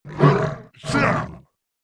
Index of /App/sound/monster/orc_general
attack_2.wav